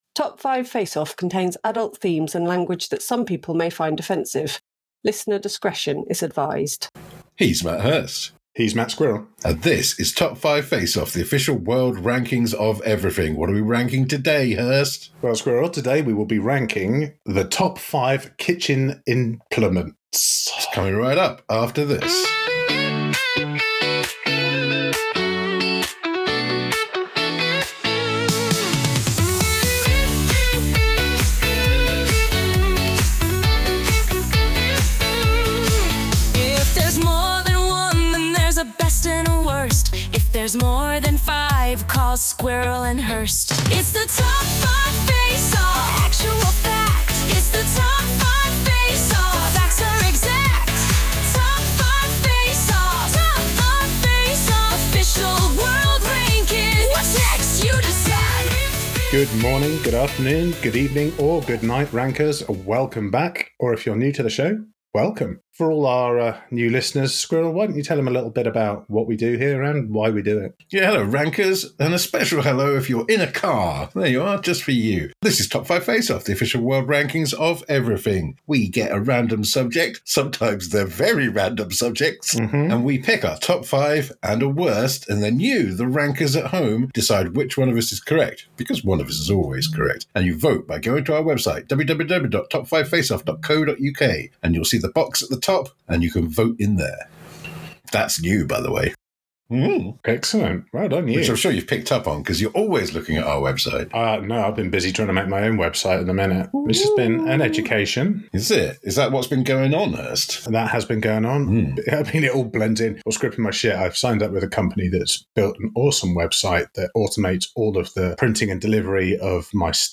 1 Roll for Mutation: Post-Apocalyptic TTRPG History: Ep. 3 Paranoia 57:28 Play Pause 9d ago 57:28 Play Pause Play later Play later Lists Like Liked 57:28 Note: we had some audio issues with this recording (apologies)!